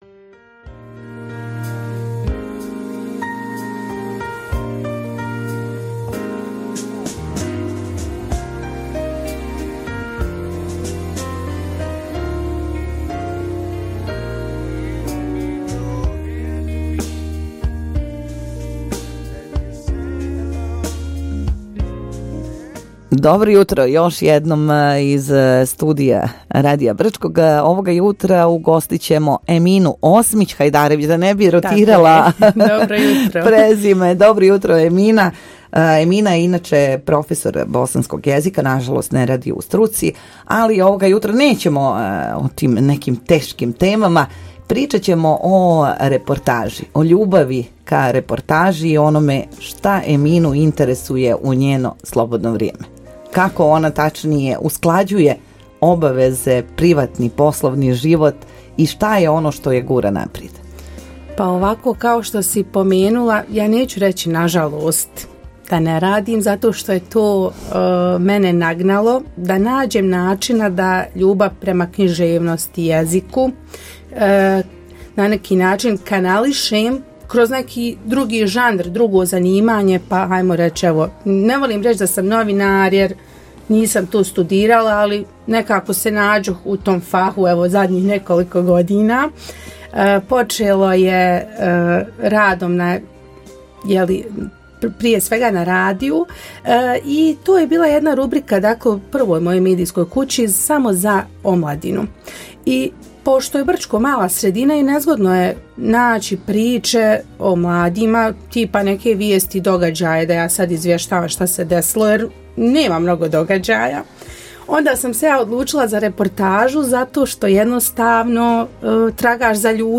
Emisija “Jutros sa vama” – Razgovor